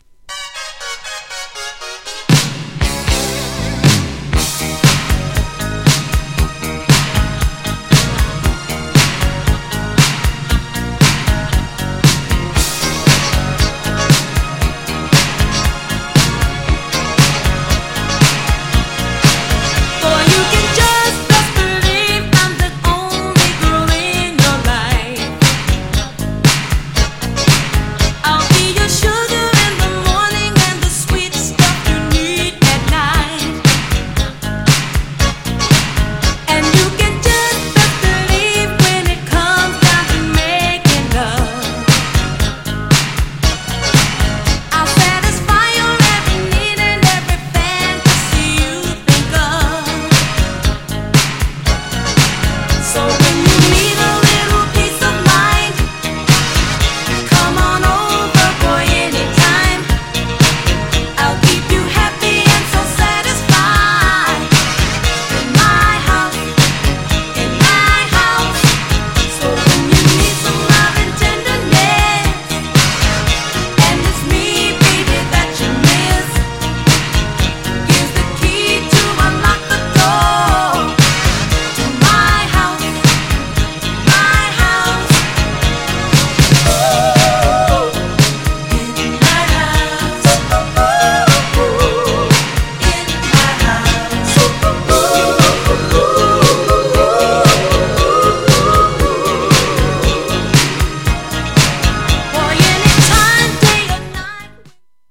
GENRE Dance Classic
BPM 131〜135BPM
GROOVY
泥臭い